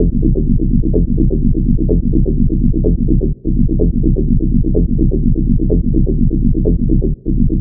Index of /90_sSampleCDs/Club_Techno/Bass Loops
BASS_126_1-C.wav